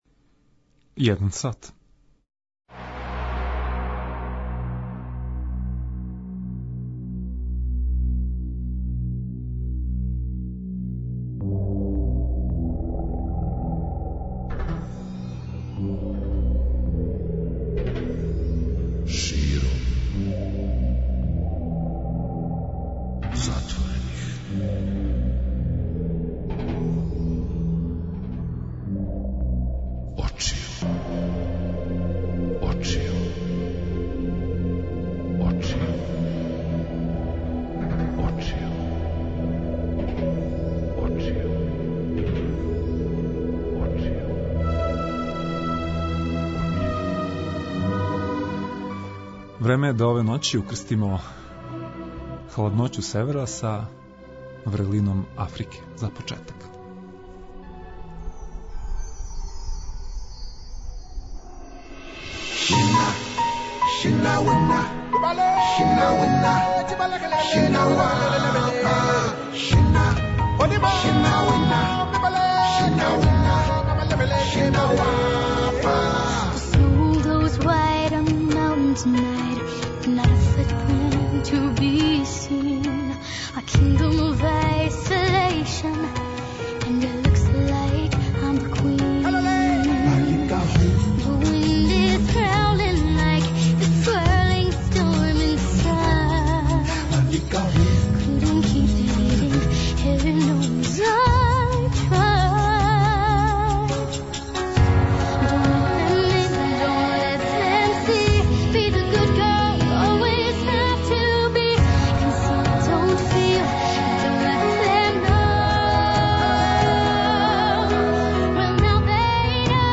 Захваљујући снимцима водимо вас на промоцију књига Лазара Комарчића, аутора првог српског СФ романа ("Једна угашена звезда" из 1902. године), представљање другог броја стрипа "Косингас" и новим пројектима Удружења грађана ''Фанови научне фантастике'' из Београда.